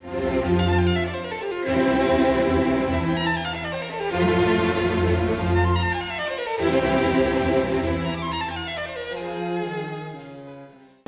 Third Movement: Allegro - F Major
The final solo section depicts 'The Wild Beast, in fleeing, dies', by a series of descending demi-semiquaver scales: